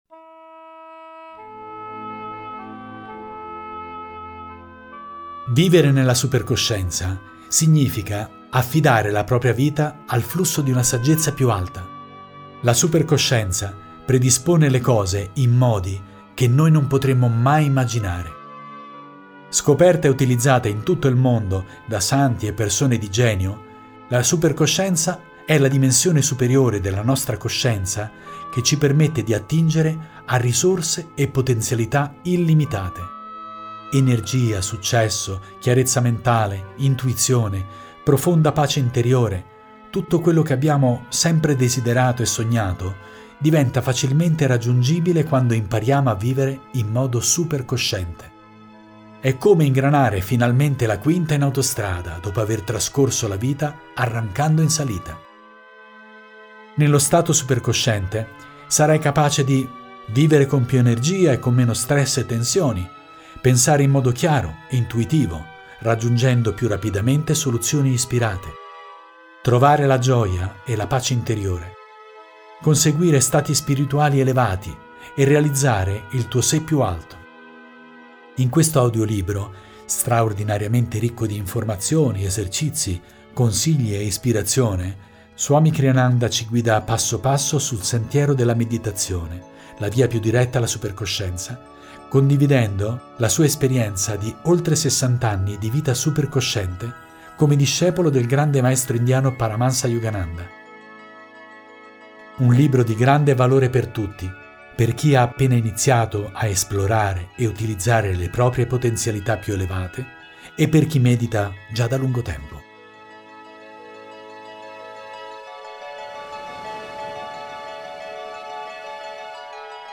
audiolibro
Registrato presso il Jyoti Studio di Ananda Assisi nel 2025.